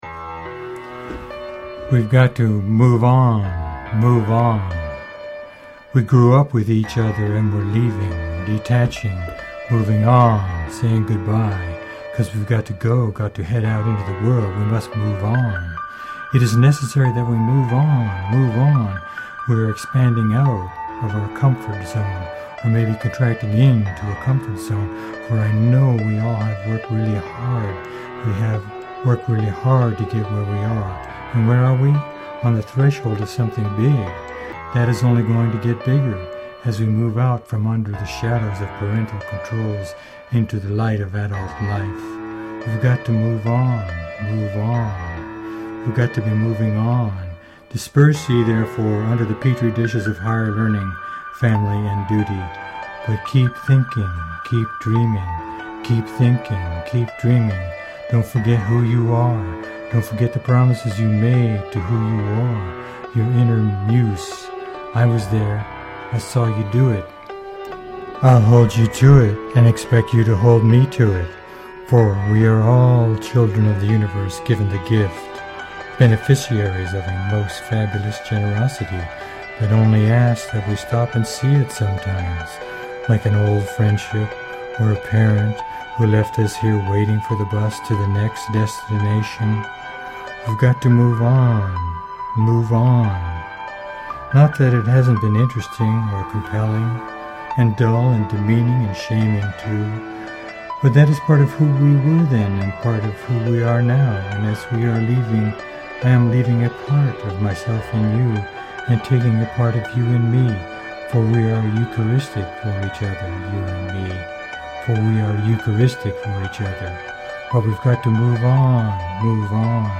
Spoken Word
original piano composition
Spoken Word with Ambient Chill music,